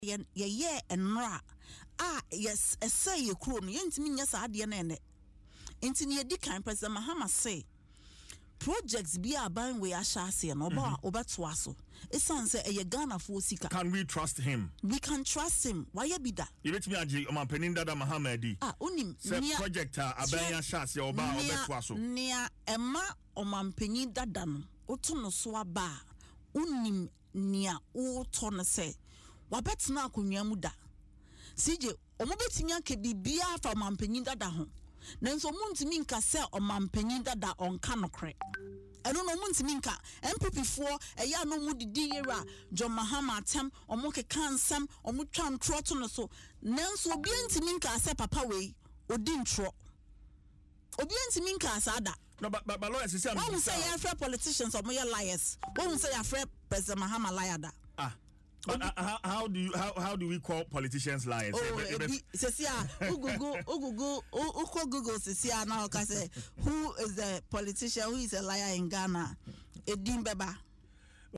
She made these remarks on Adom FM’s Dwaso Nsem as she declared the commitment of the NDC leader to continue all projects of his predecessor when elected on December 7.